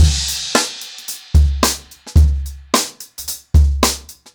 HarlemBrother-110BPM.1.wav